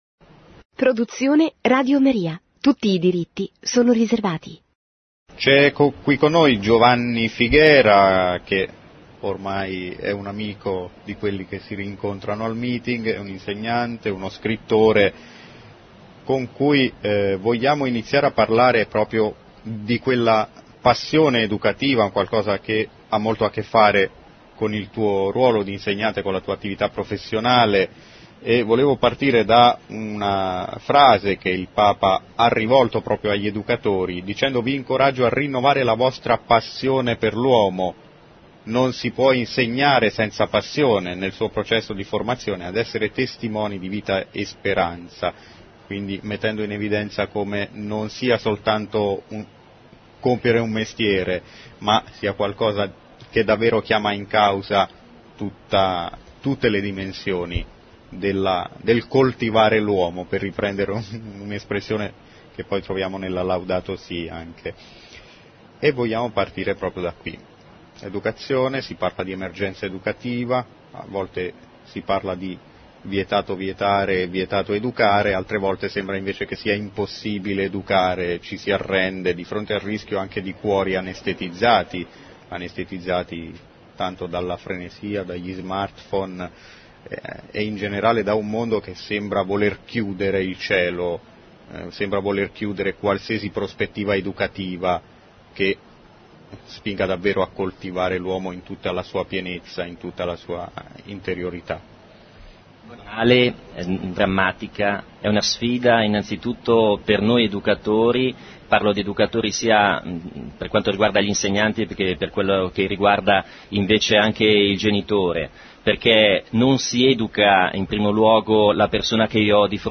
durante il Meeting di Rimini 2015